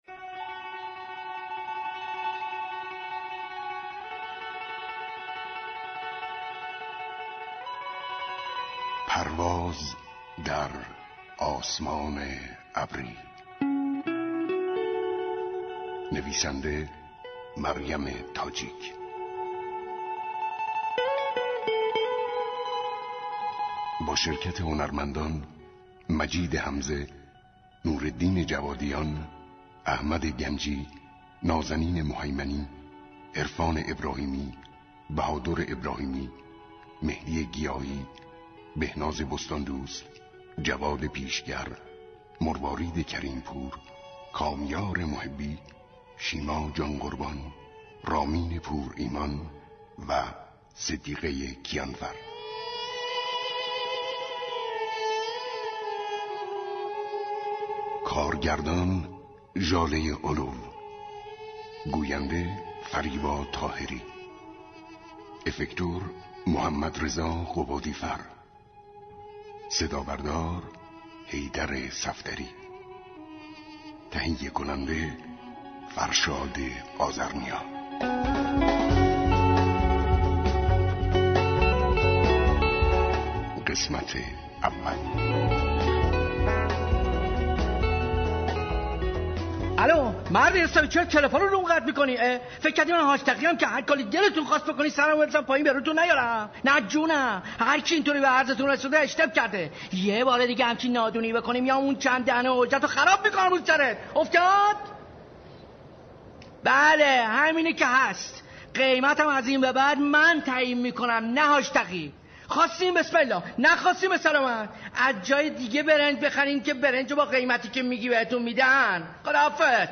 سه شنبه 13 شهریور شنونده سریال رادیویی